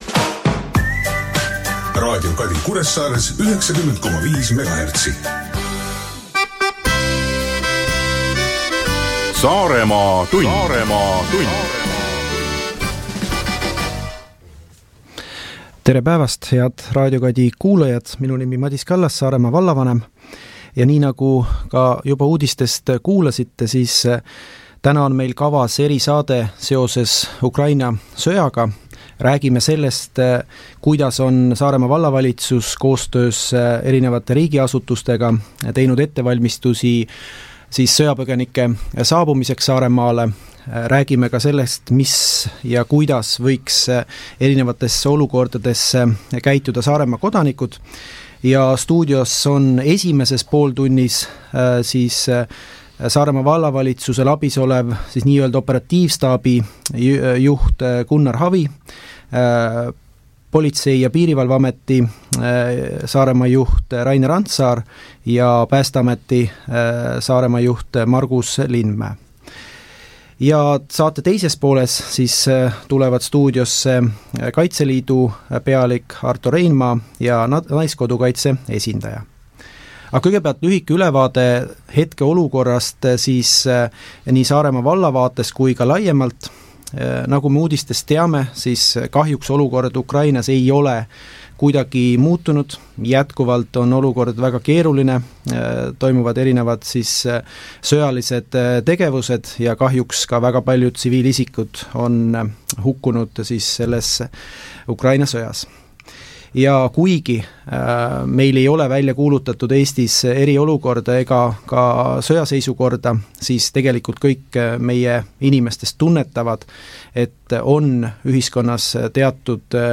Stuudios on Saaremaa vallavalitsuse, politsei- ja piirivalveameti, päästeameti, Eesti Punase Risti ning Kaitseliidu ja naiskodukaitse esindajad.